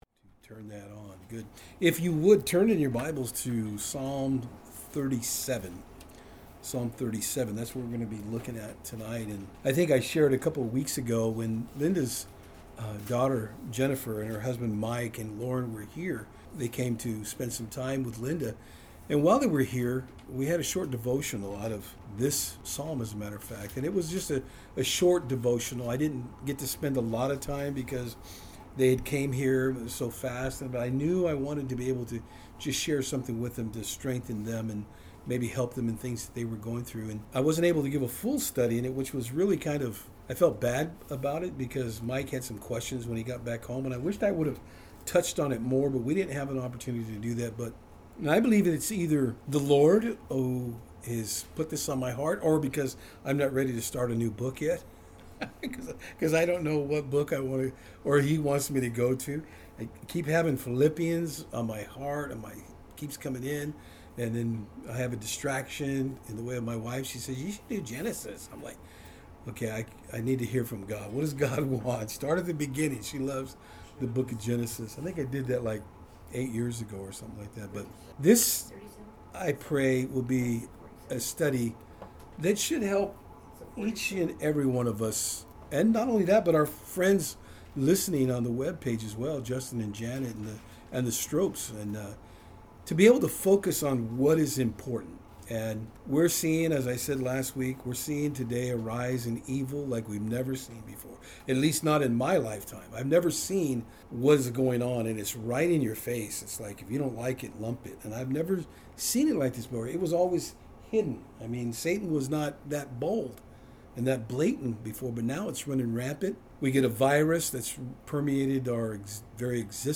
Special Message
Service Type: Saturdays on Fort Hill